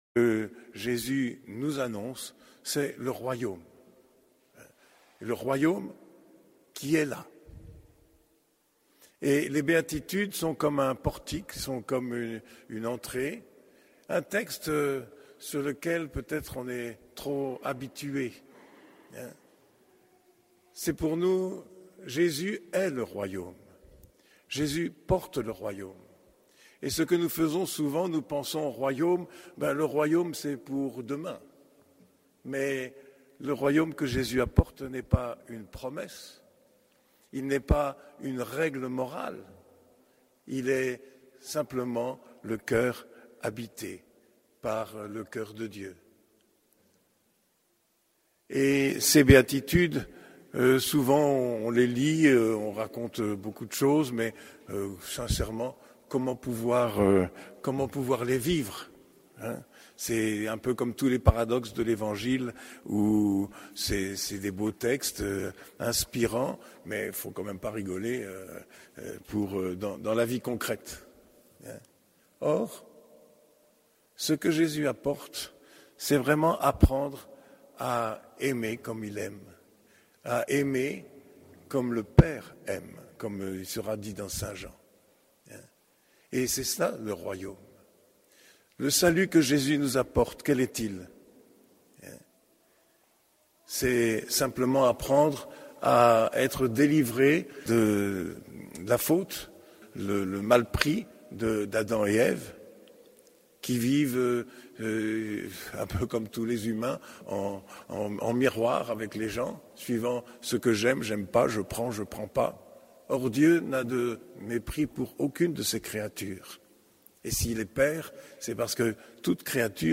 Homélie du 4e dimanche du Temps Ordinaire